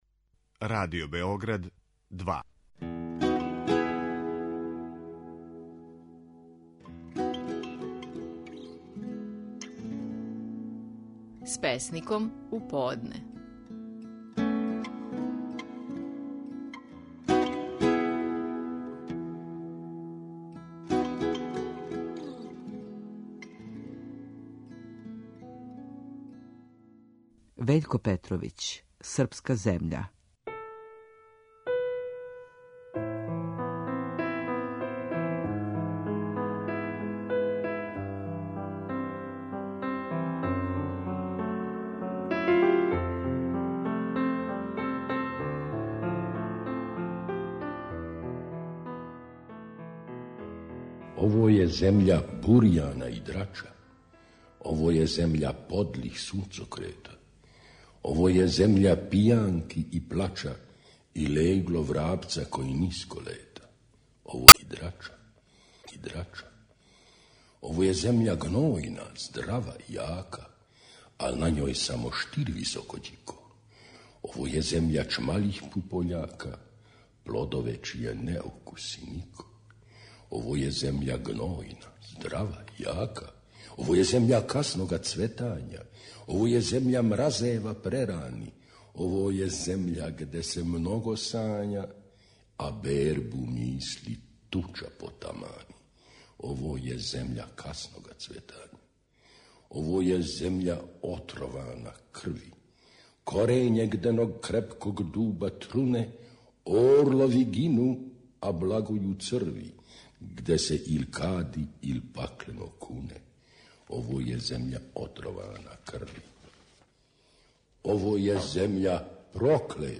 Наши најпознатији песници говоре своје стихове
Песник Вељко Петровић говорио је стихове своје песме "Српска земља".